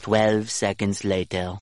Category Sound Effects